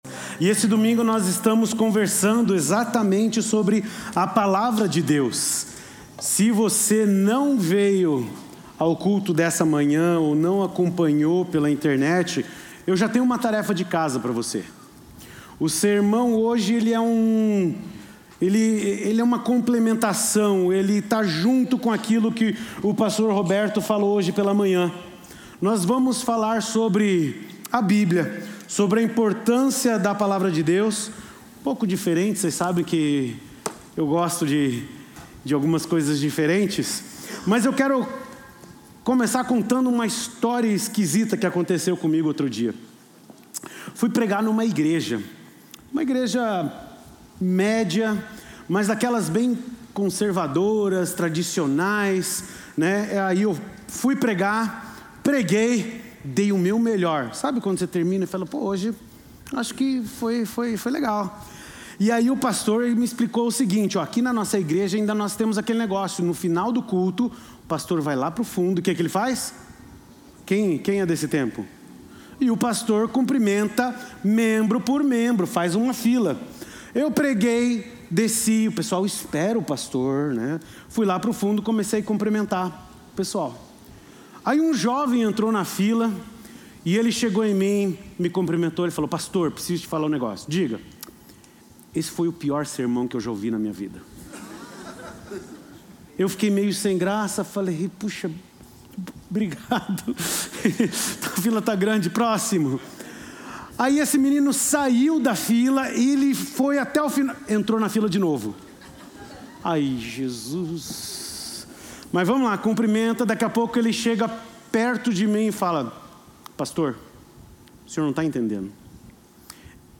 O pior sermão do mundo